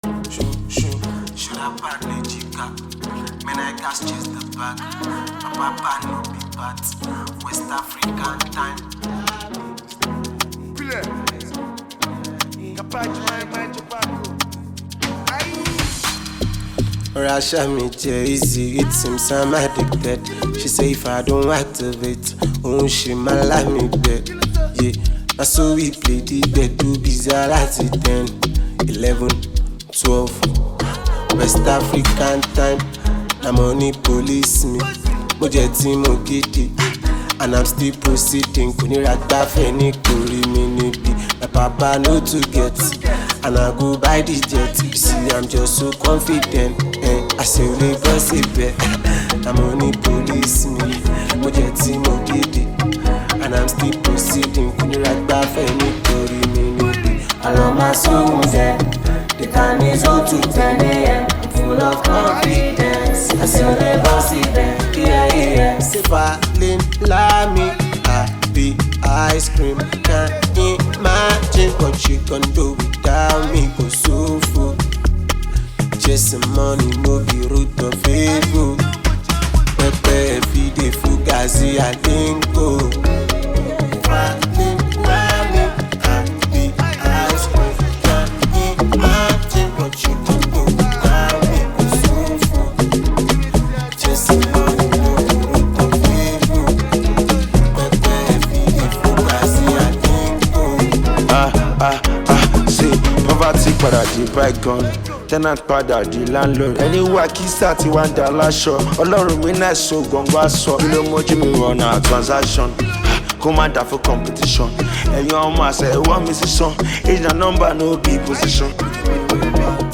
Nigerian talented rap artist